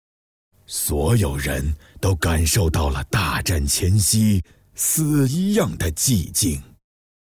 [CG人声]
神罚之门【斗战神 – 神罚之门】：是斗战神系列宣传片的旁白，旁白采用粗犷厚重、富有磁性的中年男人音色，营造神秘威严氛围。